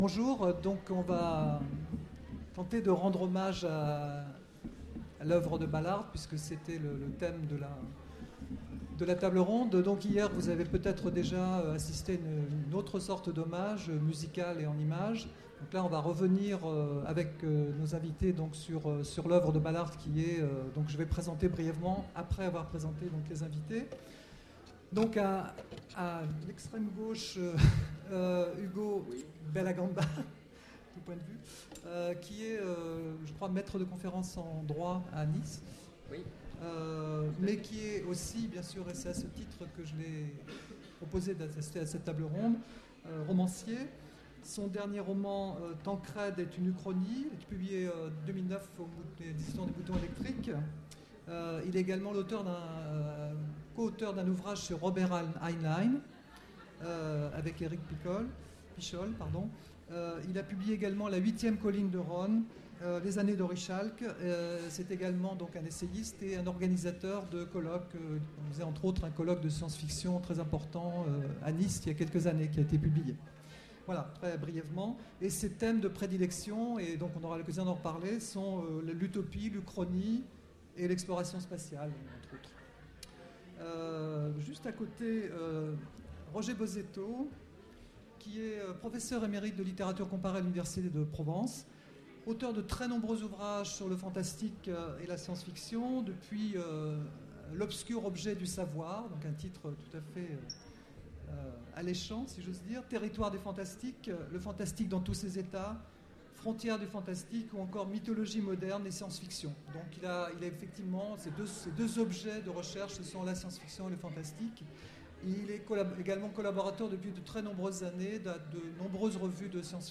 Voici l'enregistrement de la conférence : Hommage à J.G.Ballard aux Utopiales 2009.